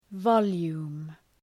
Προφορά
{‘vɒlju:m}